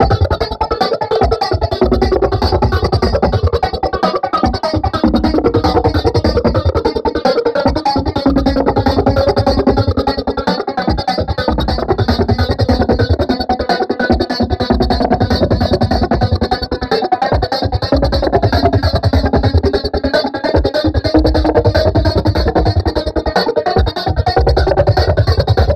loop drum experiment 149bpm
abstract drum glitch loop weird sound effect free sound royalty free Gaming